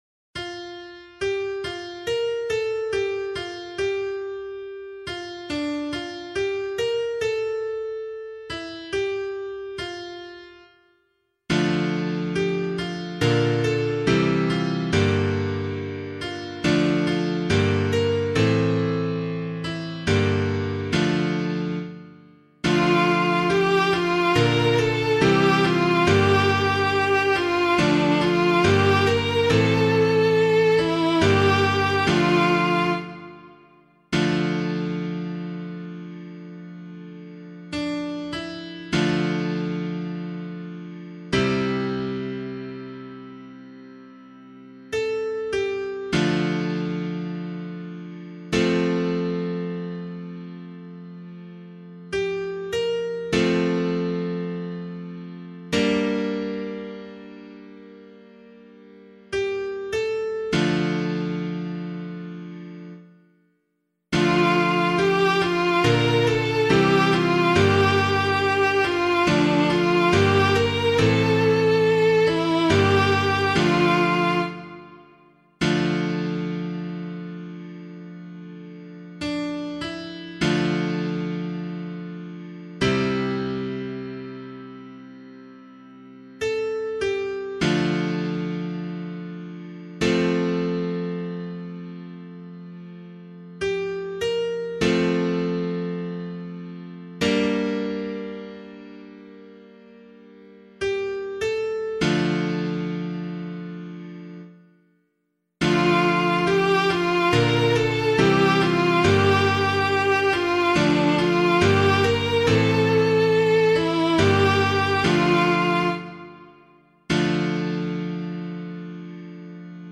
215 ANZAC Day Psalm B [LiturgyShare 1 - Oz] - piano.mp3